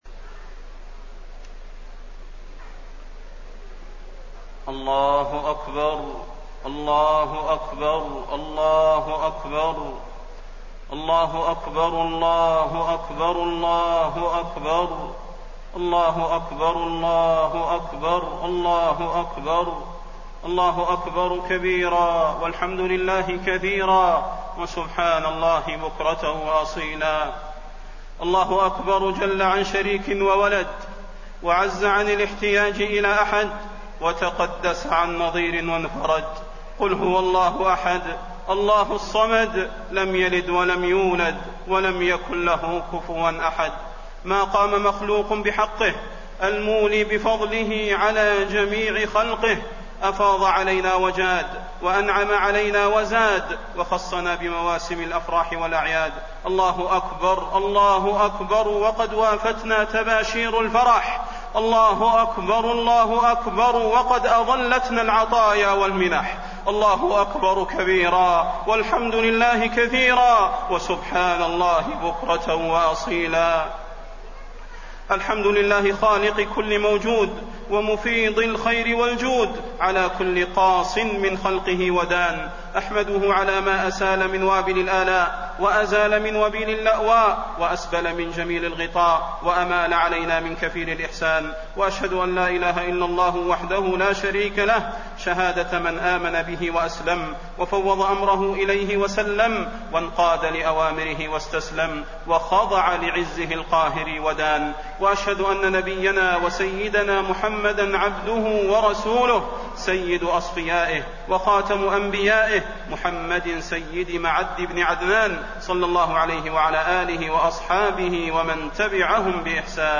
خطبة عيد الفطر - المدينة - الشيخ صلاح البدير
تاريخ النشر ١ شوال ١٤٣٠ هـ المكان: المسجد النبوي الشيخ: فضيلة الشيخ د. صلاح بن محمد البدير فضيلة الشيخ د. صلاح بن محمد البدير خطبة عيد الفطر - المدينة - الشيخ صلاح البدير